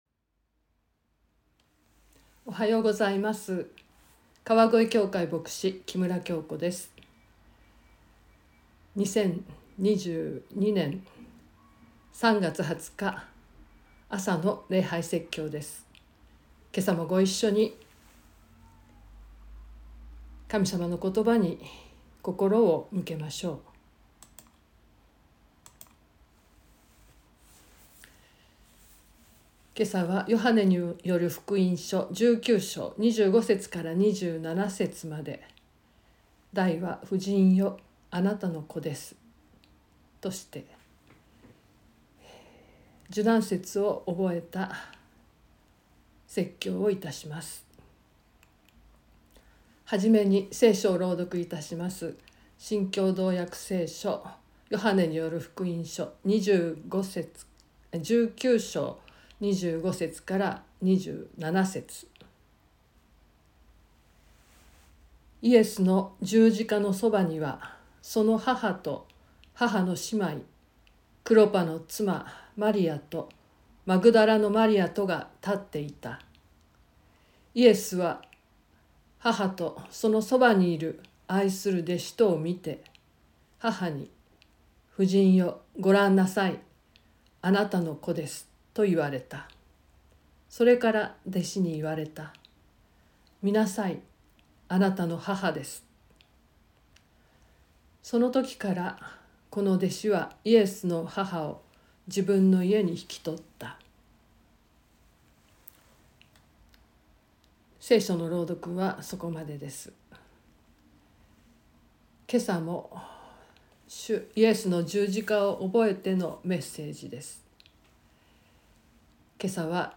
2022年03月20日朝の礼拝「婦人よあなたの子です」川越教会
川越教会。説教アーカイブ。